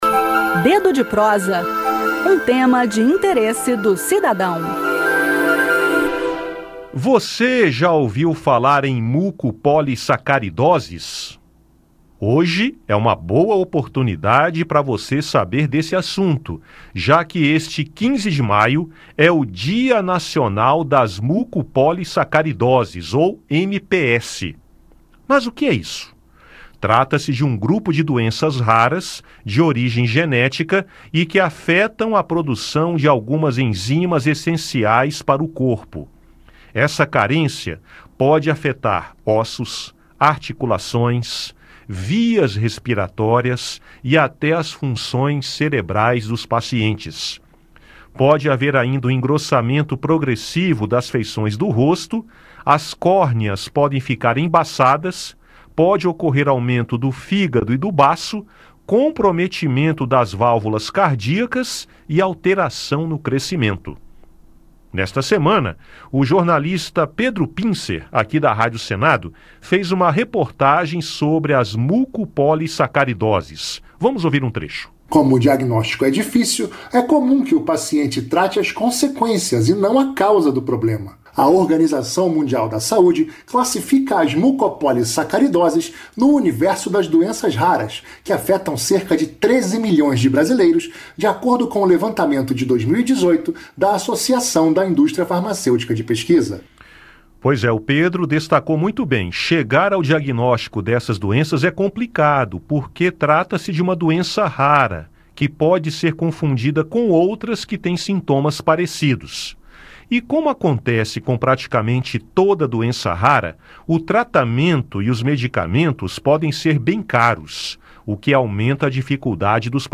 No quadro "Dedo de Prosa" do Conexão Senado desta sexta-feira (15), o tema debatido é o Dia Nacional de Conscientização das Mucopolissacaridoses (MPS), celebrado neste 15 de maio em virtude da Lei 13.122, de 2015. As mucopolissacaridoses são um grupo de doenças raras de origem genética, que afetam cerca de 13 milhões de brasileiros.